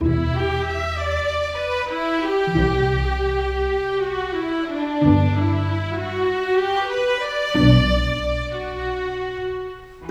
Rock-Pop 09 Strings 01.wav